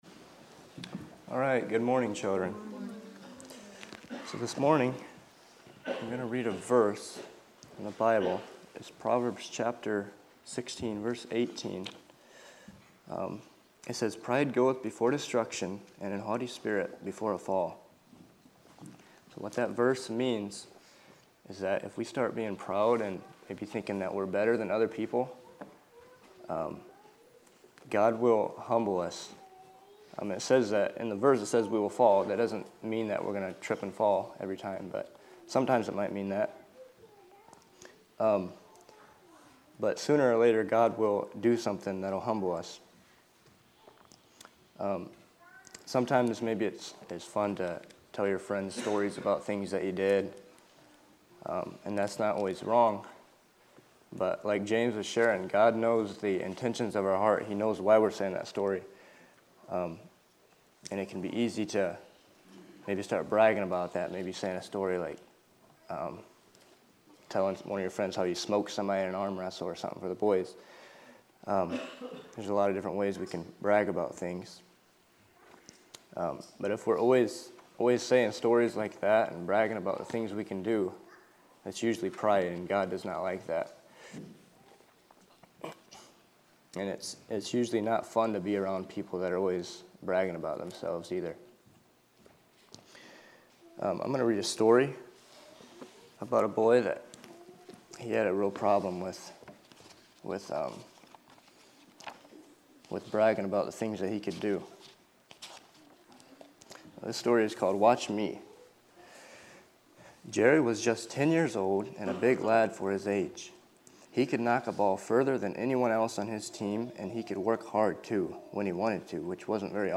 Children's Lessons